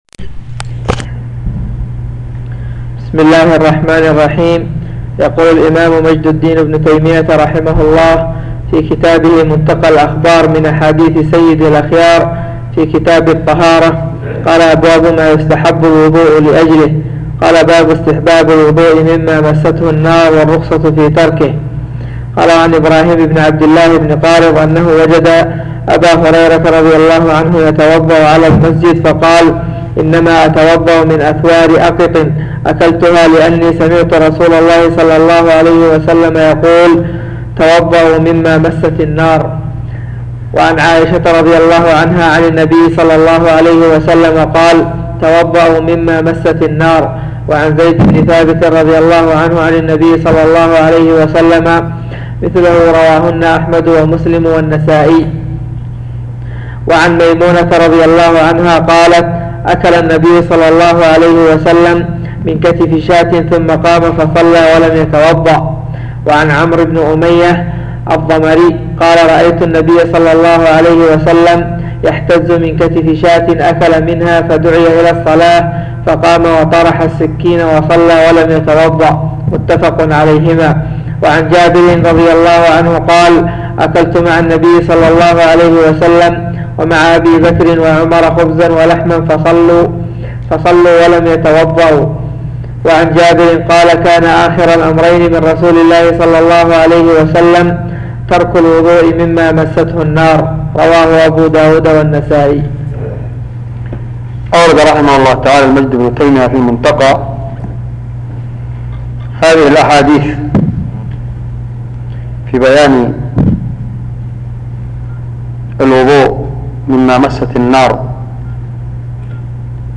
تسجيل لدروس شرح كتاب كتاب الطهارة - نيل الأوطار شرح منتقى الأخبار من أحاديث سيد الاخيار